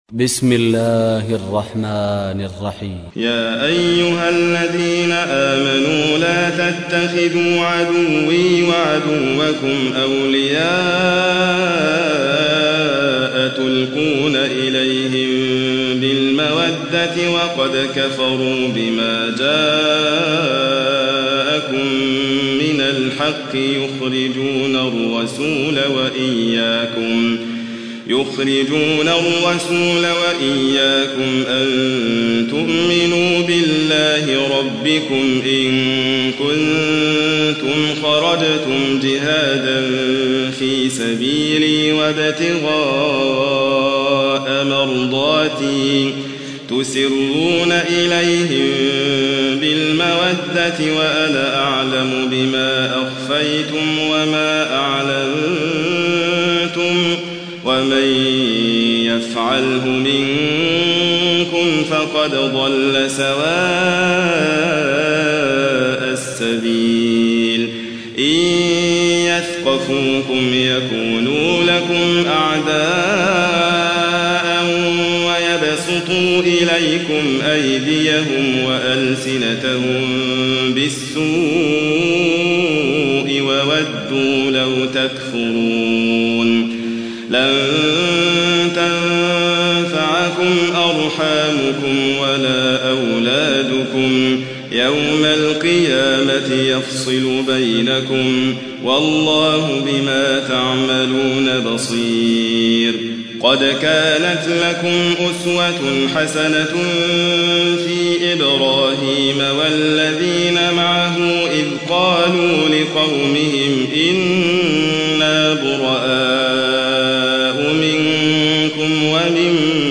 تحميل : 60. سورة الممتحنة / القارئ حاتم فريد الواعر / القرآن الكريم / موقع يا حسين